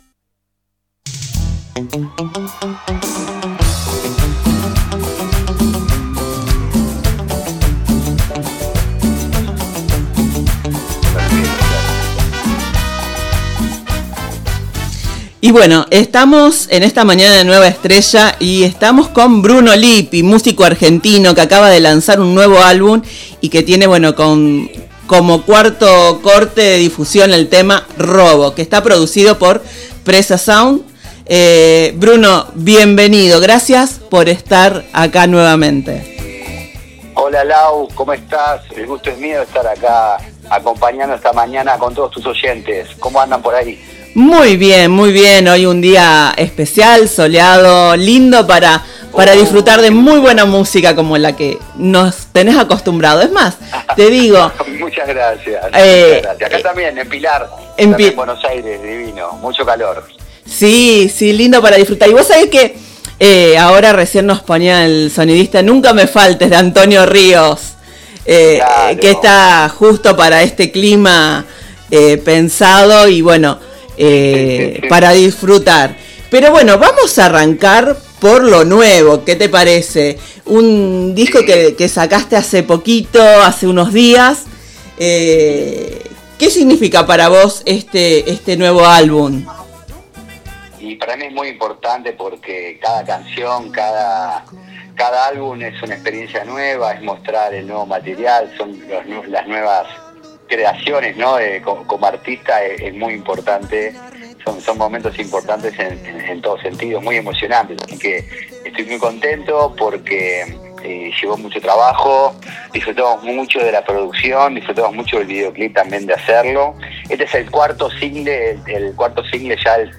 presentó su nuevo material en una entrevista